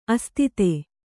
♪ astite